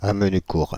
Amenucourt (French pronunciation: [am(ə)nykuʁ]